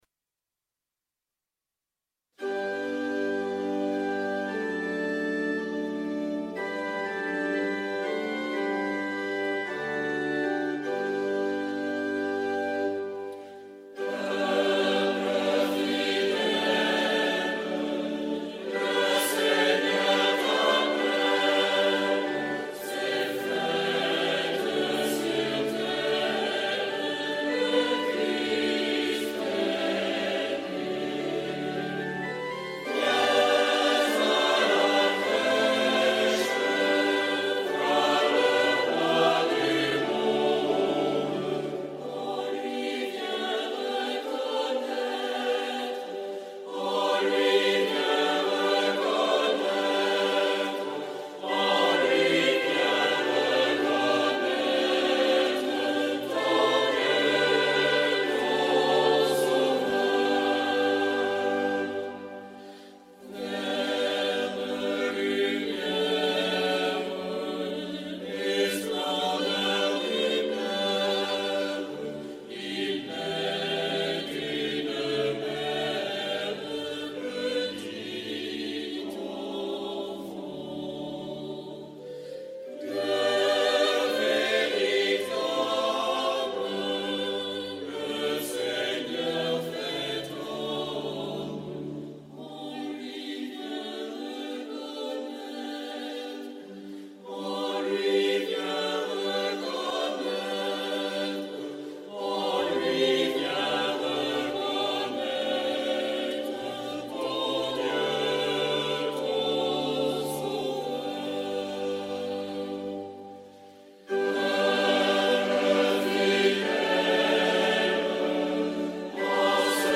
Eglise Saint-François-de-Paule Fréjus - Veillée de Noël et messe de minuit de la Nativité de Notre Seigneur
Chapelle Saint-François-de-Paule - Place Agricola - Fréjus